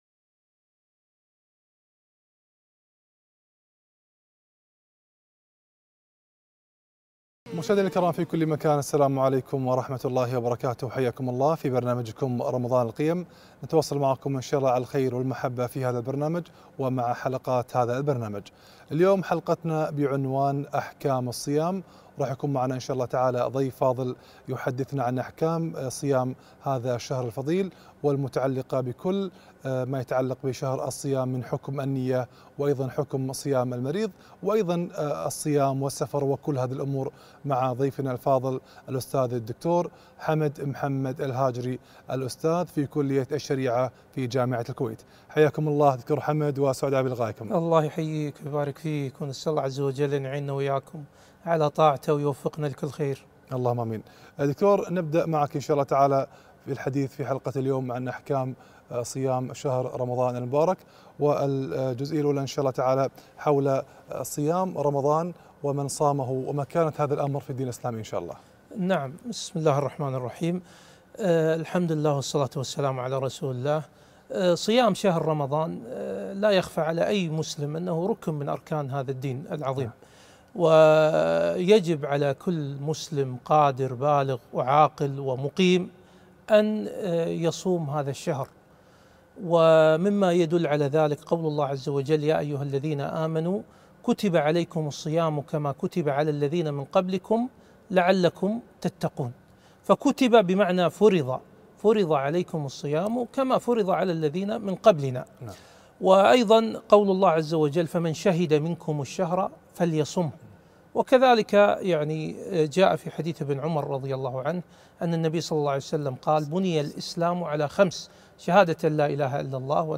أحكام الصيام - لقاء إذاعي برنامج رمضان القيم